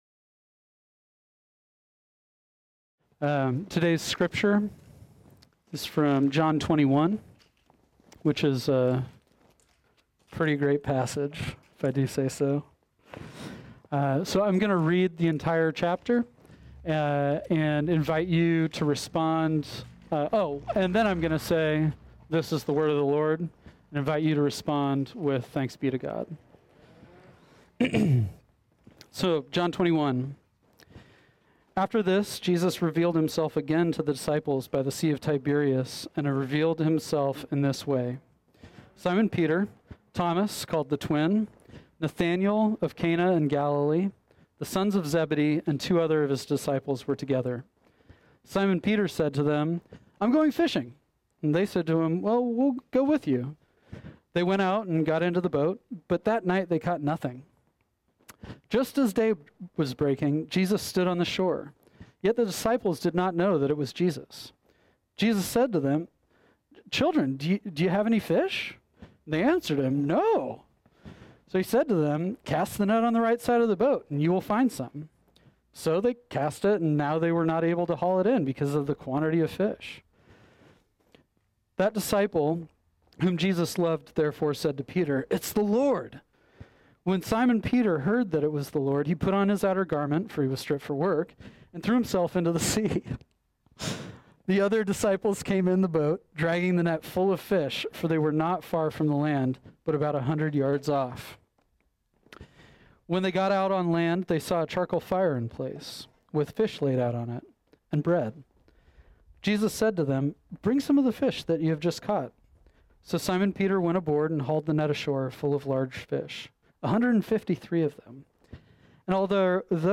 This sermon was originally preached on Sunday, August 30, 2020.